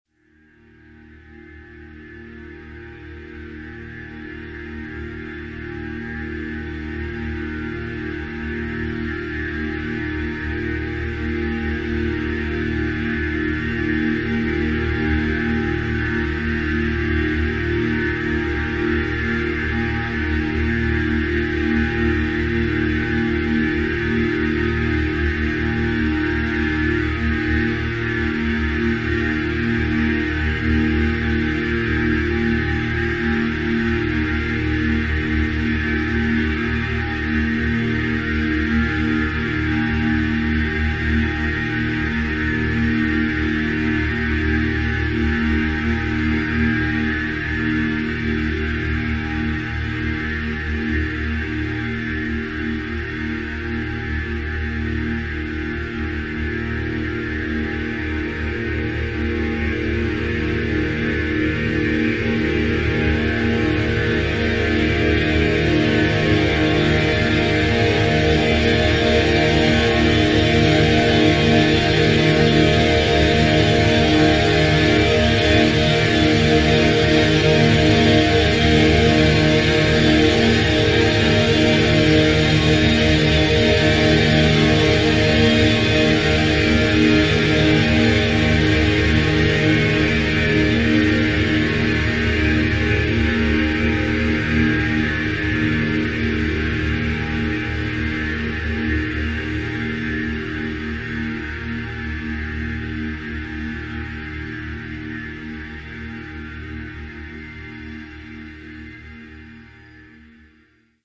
MP3s operate in a similar way to images, tap the link below to play a short instrumental track in Arianes music player, as with images you can click the overflow menu to save the file locally: